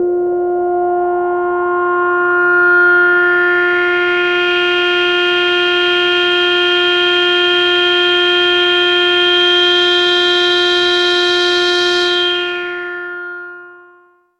标签： FSharp3 MIDI音符-55 Korg的 - 单 - 保利 合成 单注 多重采样
声道立体声